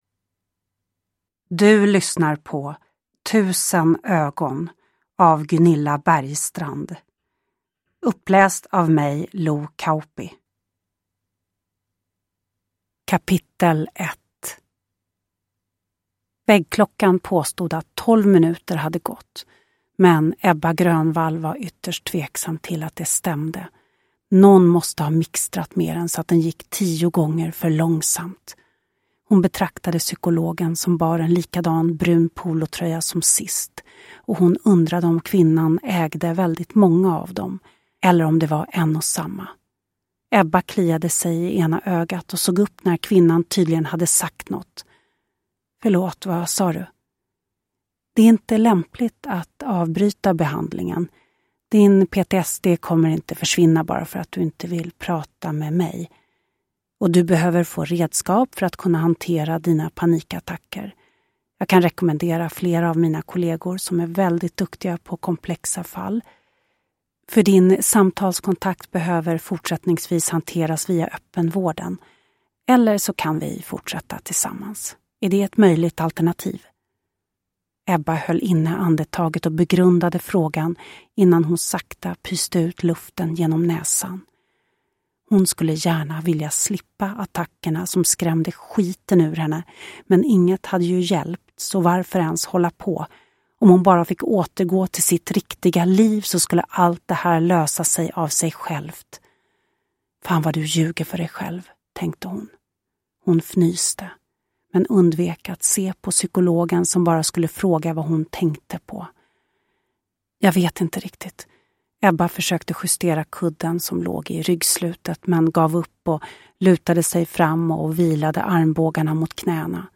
Tusen ögon – Ljudbok
Uppläsare: Lo Kauppi